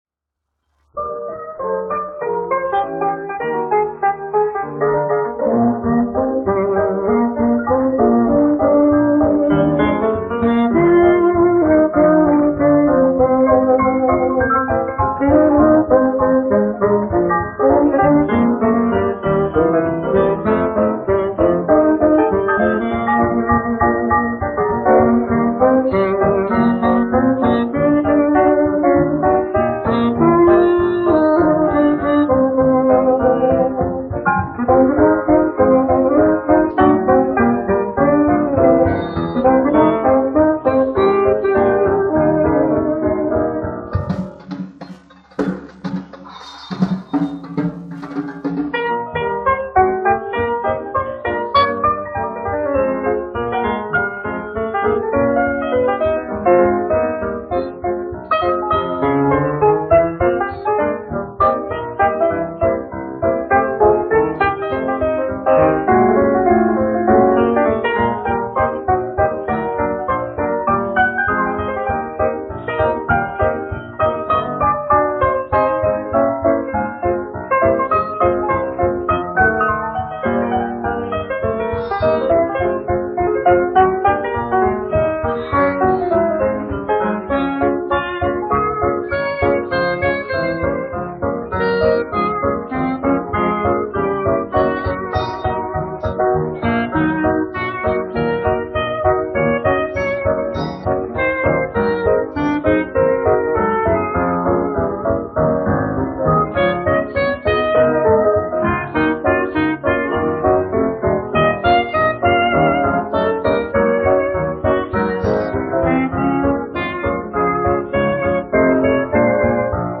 1 skpl. : analogs, 78 apgr/min, mono ; 25 cm
Fokstroti
Populārā instrumentālā mūzika
Skaņuplate